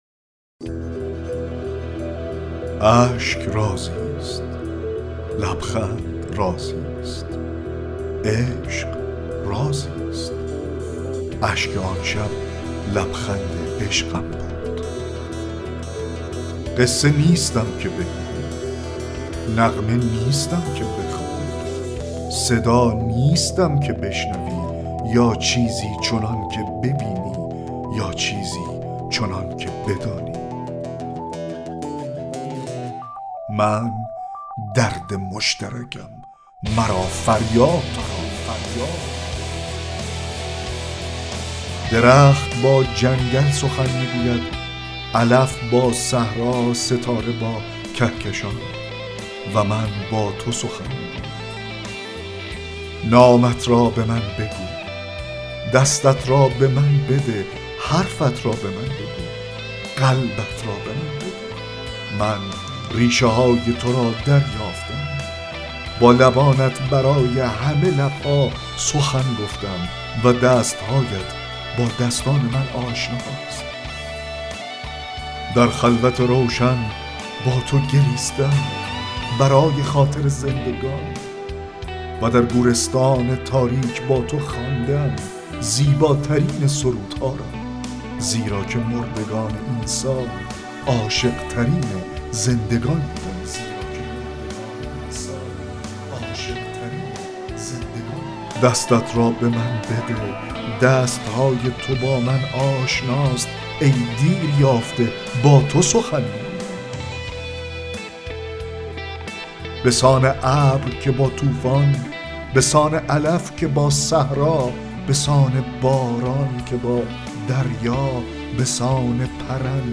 4- دکلمه شعر عشق عمومی(عشق رازیست…)